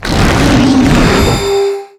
Cri d'Ama-Ama dans Pokémon Ultra-Soleil et Ultra-Lune.